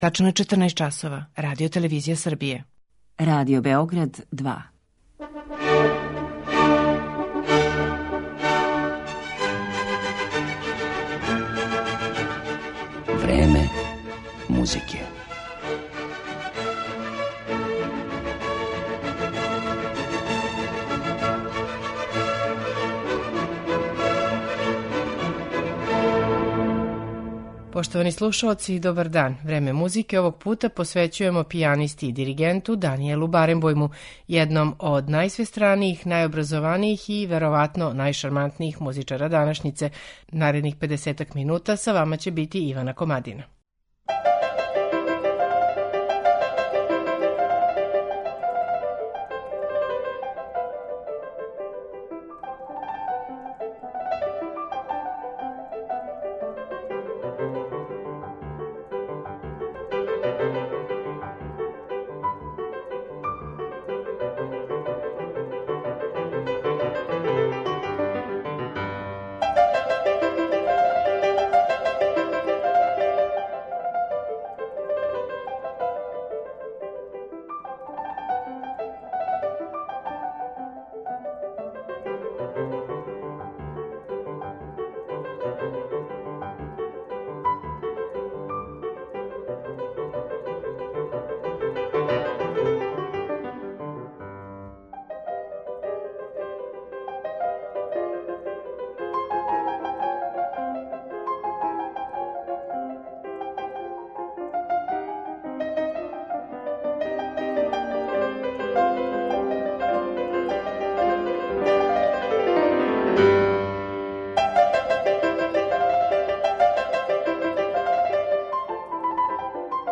пијаниста и диригент